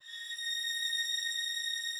strings_082.wav